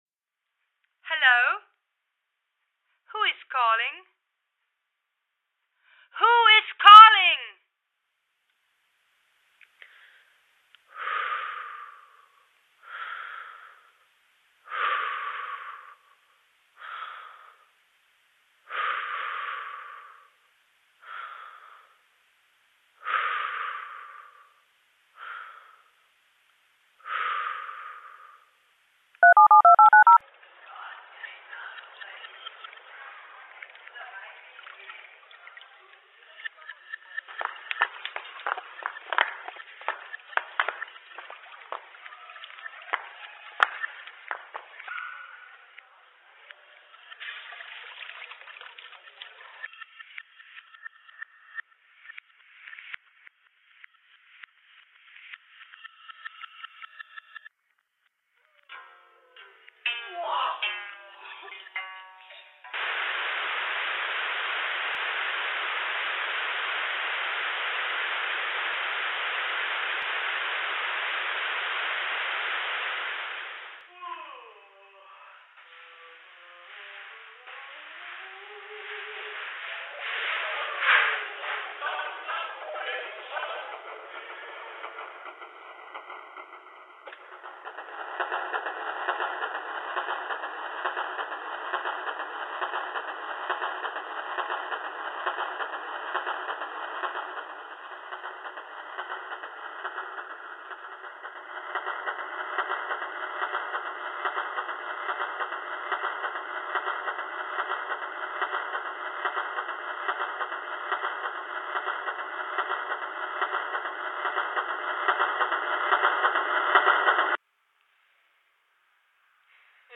Pronto uses a simple, everyday object for its artistic formation and, through sound intervention, evokes a current of the subconscious in the viewer - memories of communicating with the world, one's homeland, "all the little things", telephone cards, and so on.